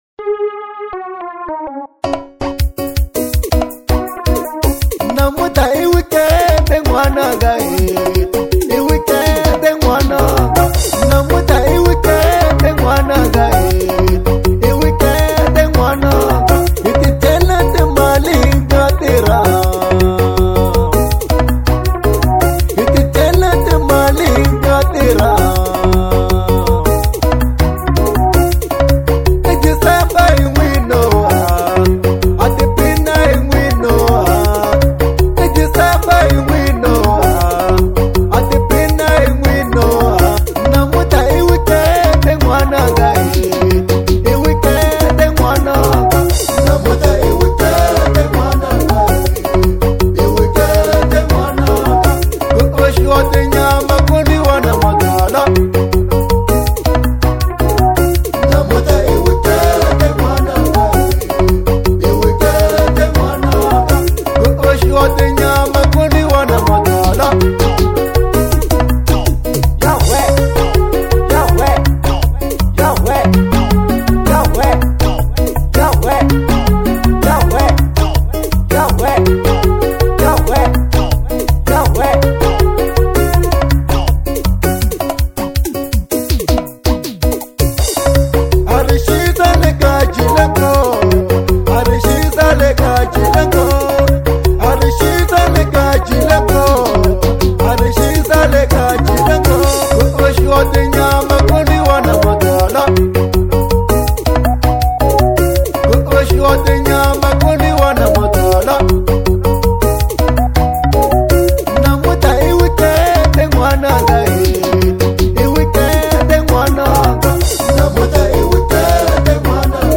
03:17 Genre : Xitsonga Size